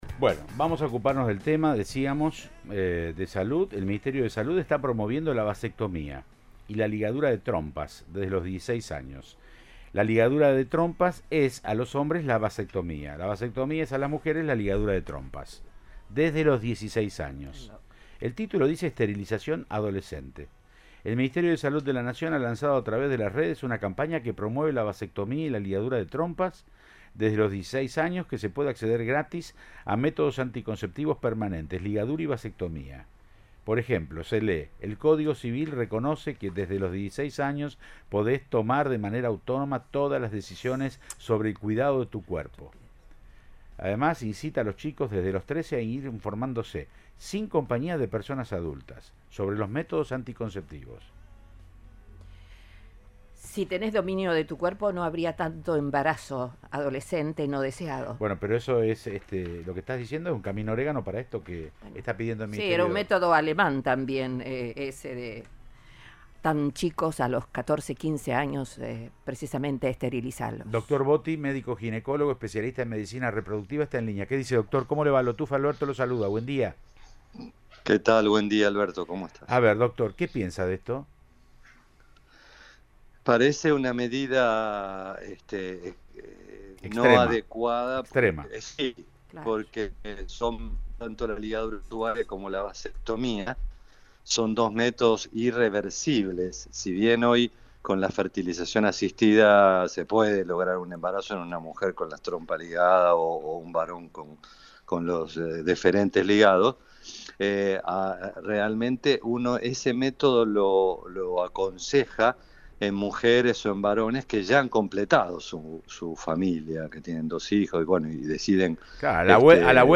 cuestionó esa iniciativa en una entrevista en Siempre Juntos, por Cadena 3 Rosario.